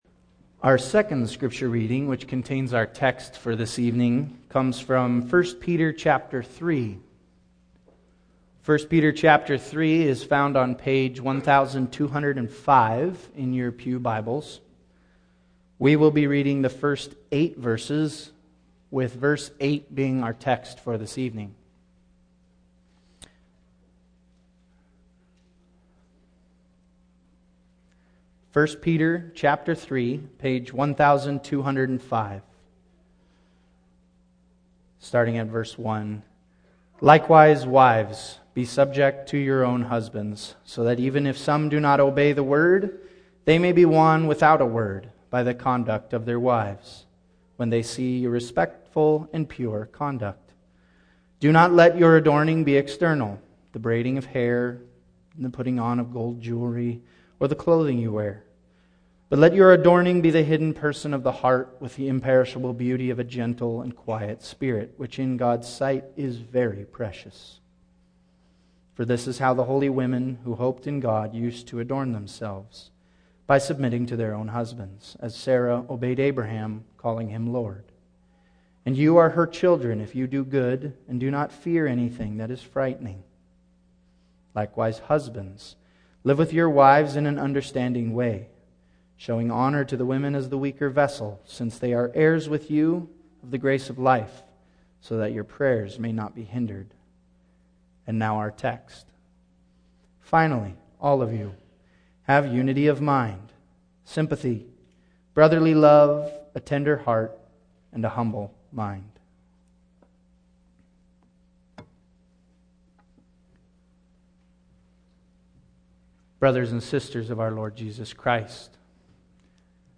Single Sermons Passage: 1 Peter 3:1-8 Service Type: Evening